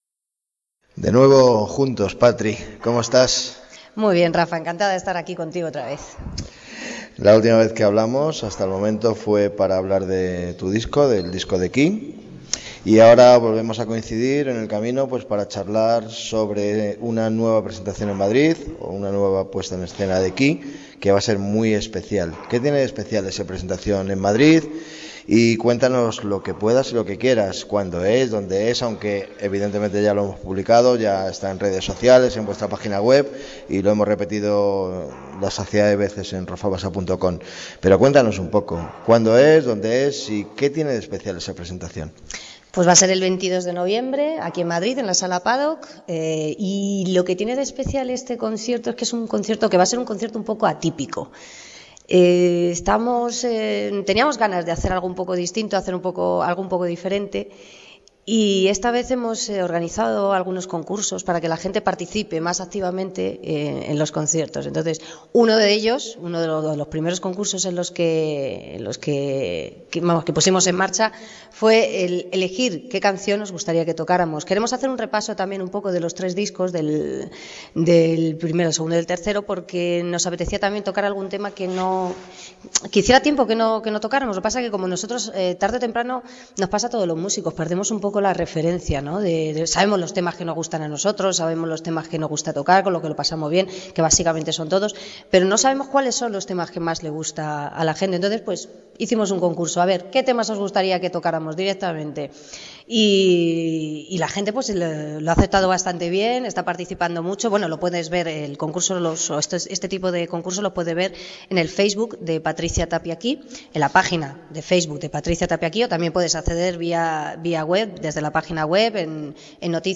Esta es la entrevista.